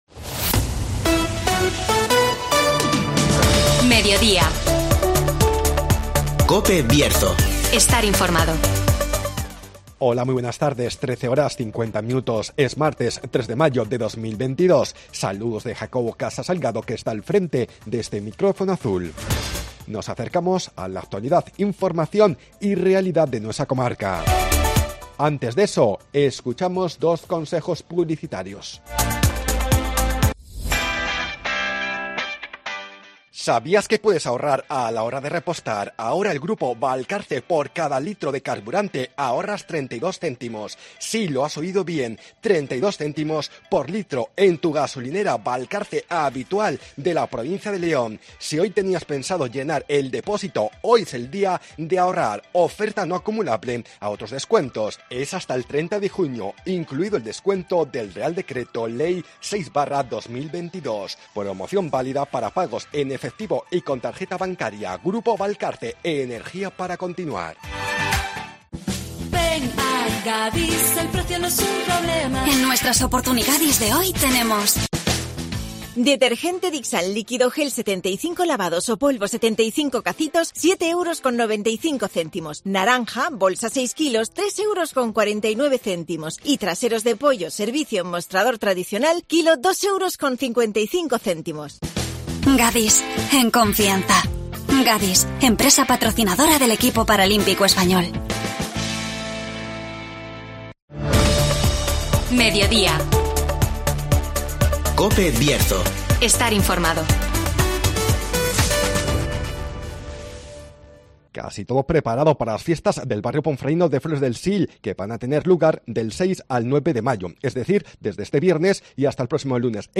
Fiestas de Flores del Sil del 6 al 9 de mayo (Entrevista